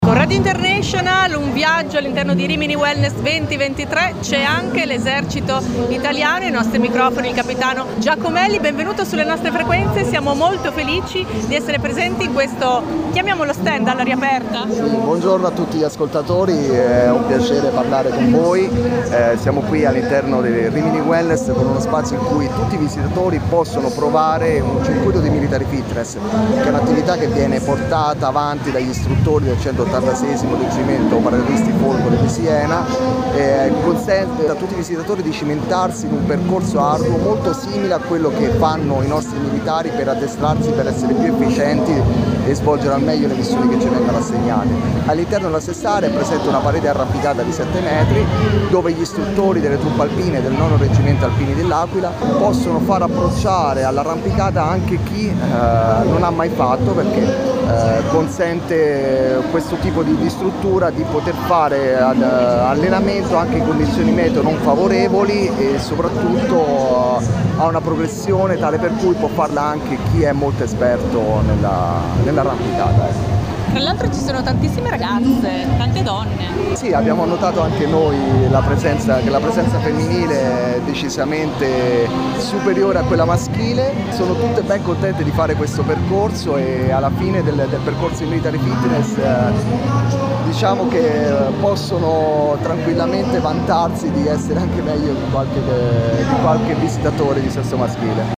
RIMINI WELNESS - Radio International Live